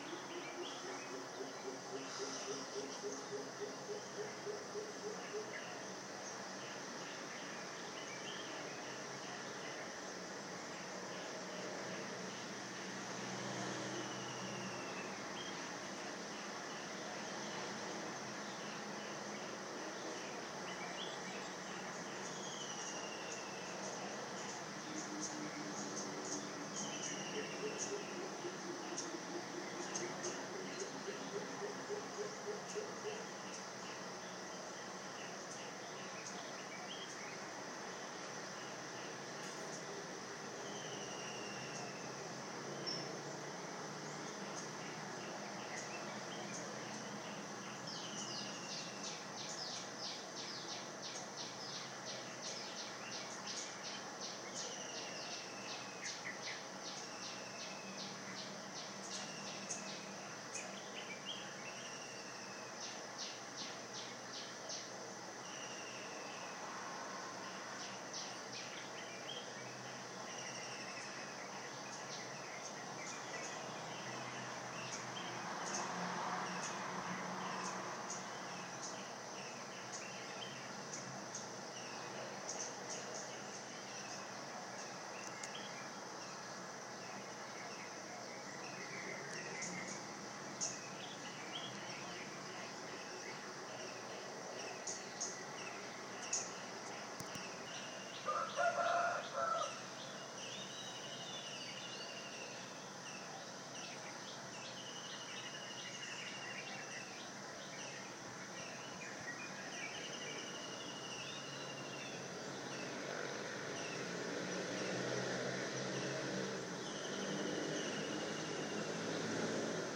Aamun ääniä parvekkeelta.